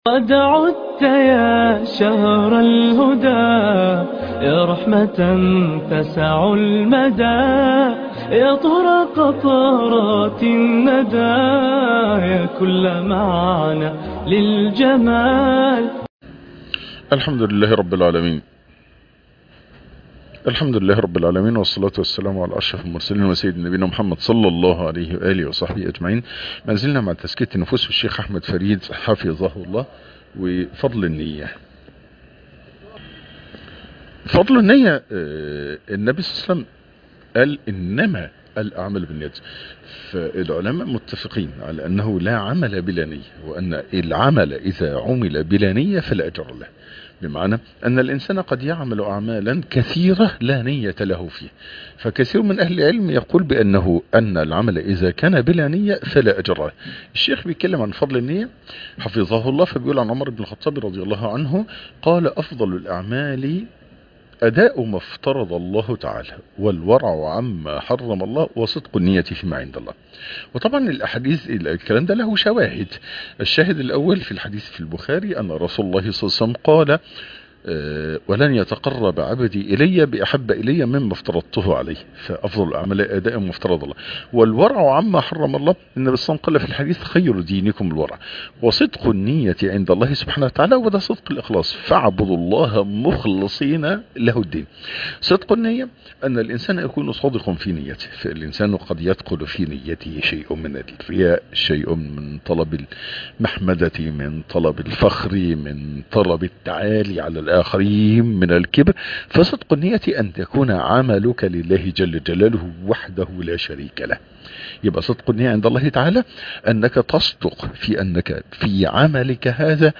تزكية النفوس -الدرس الخامس- فضل النية